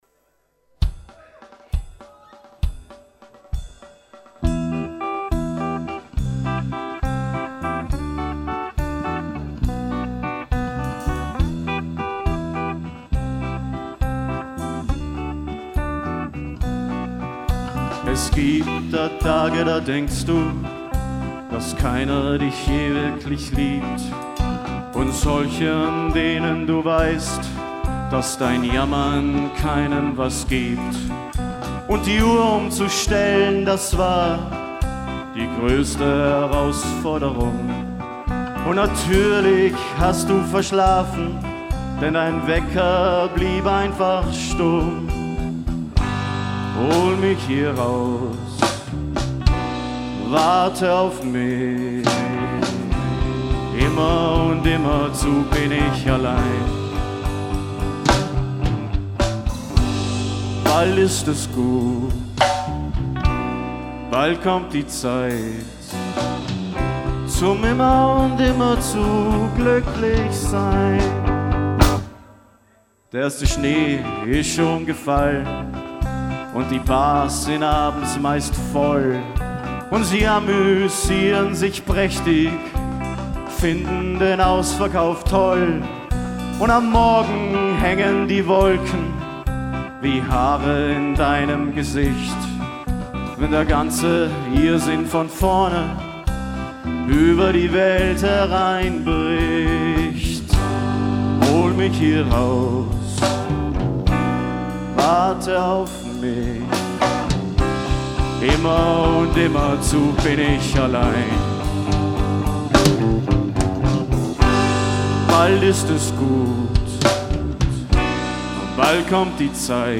Rau und brutal und toll-wütig.